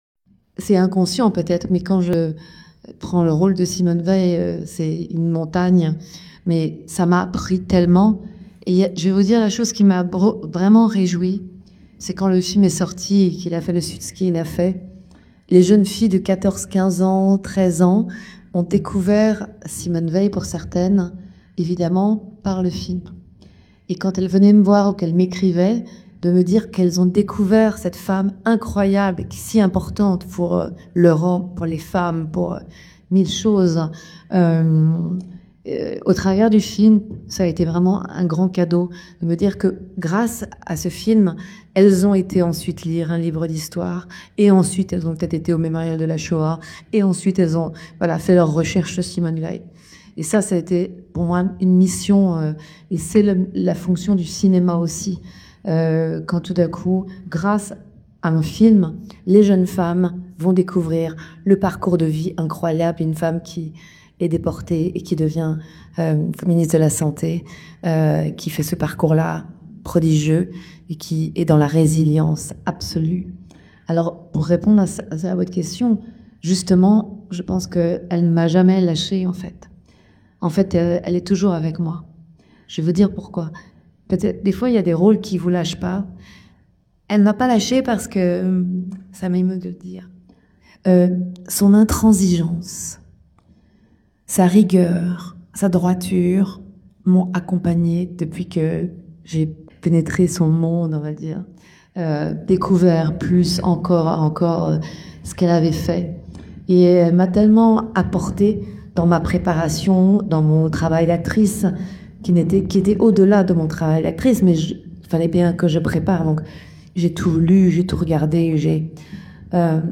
2. Podcasts cinéma : interviews | La Radio du Cinéma
À l'occasion de l'avant-première du film "c'était mieux demain" à Valence (Cinéma Pathé) et des échanges avec le public à l'issue de la projection, Elsa Zylberstein est revenue à Simone : Le Voyage du siècle (réal. Olivier Dahan) et à l'impact que cette incarnation a eu sur la suite de sa carrière, y compris pour la comédie de Vinciane Millereau.